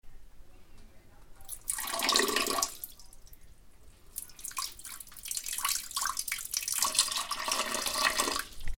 Persona miccionando
Grabación sonora del sonido producido por el chorro de una persona miccionando, o haciendo pis, en un bater
Sonidos: Acciones humanas